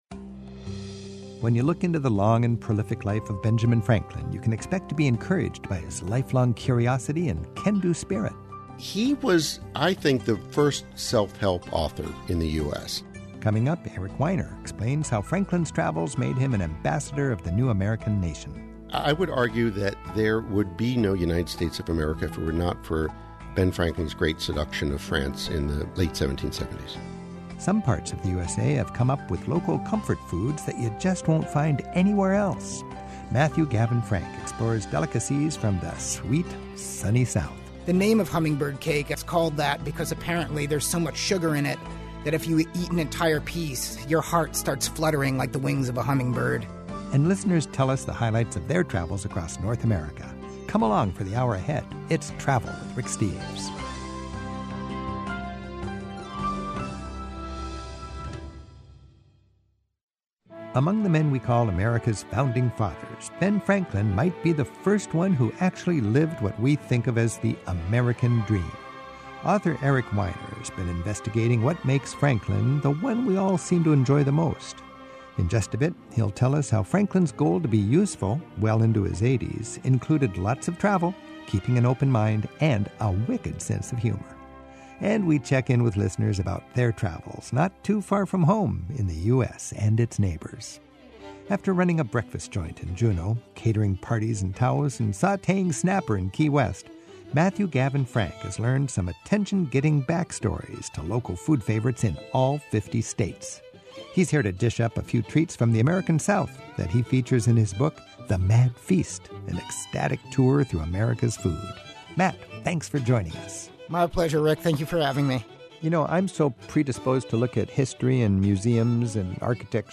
My Sentiment & Notes 760 Sweet Southern Treats; Ben Franklin and Me; Nearby Travels Podcast: Travel with Rick Steves Published On: Sat Jul 06 2024 Description: Get a taste of the hearty, homespun cuisine of the American South from a writer who has documented our nation through the foods we celebrate. Then hear about a man's quest to trace the travels of Ben Franklin — and to uncover the founder's life lessons. And listen in as callers recount meaningful travel experiences close to home in North America.